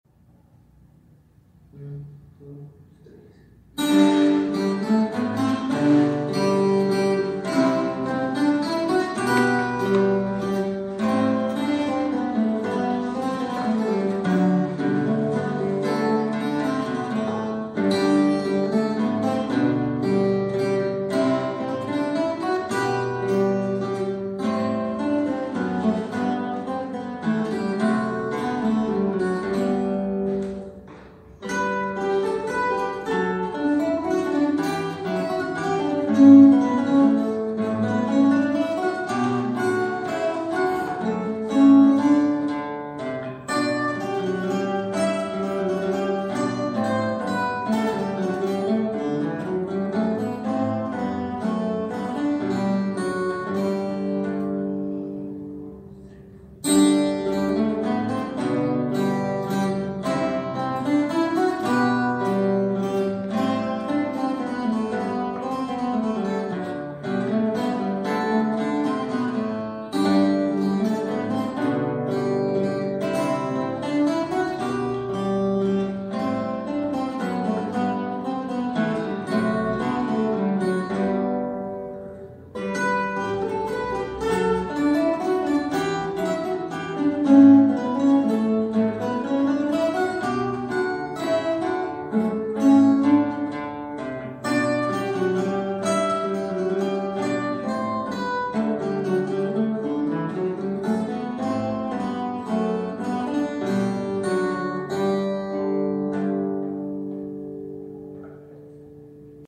Duo de viola e violão Minueto de j.s bach